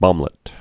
(bŏmlĭt)